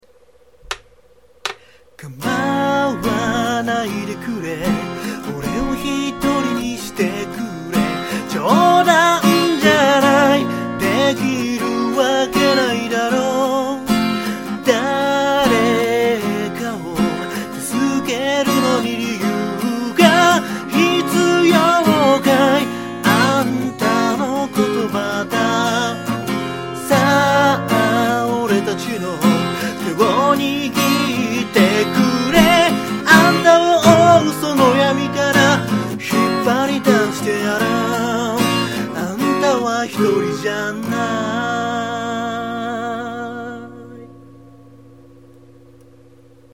録音環境が整ってないので音割れしまくるのは仕様！！